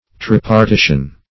Search Result for " tripartition" : The Collaborative International Dictionary of English v.0.48: Tripartition \Trip`ar*ti"tion\, n. [Cf. F. tripartition.]